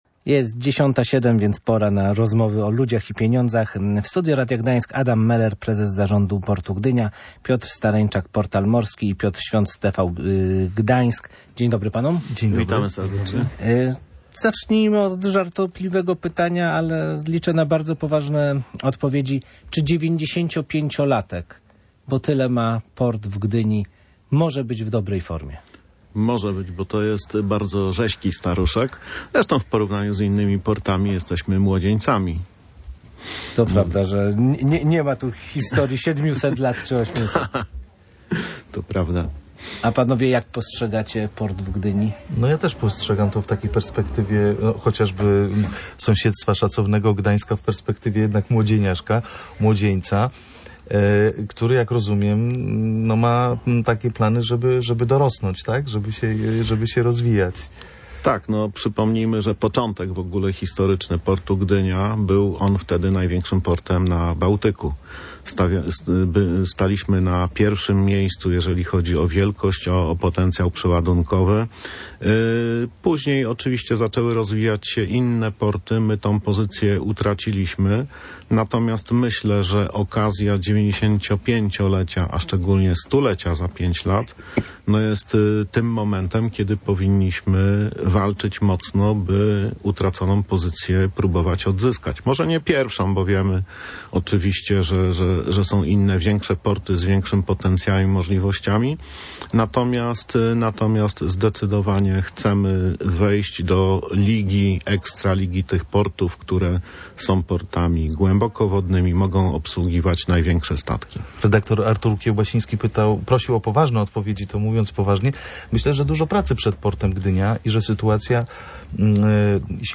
95 "urodziny" Portu Gdynia zdominowały dyskusję w audycji Ludzie i Pieniądze w Radiu Gdańsk.